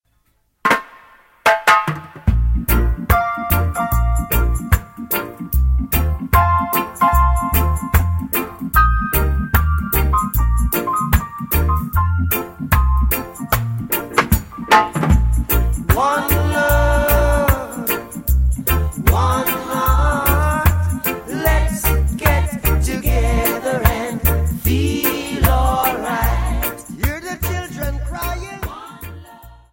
10 Hole Harmonica 4 Hole Harmonica Tunes to Play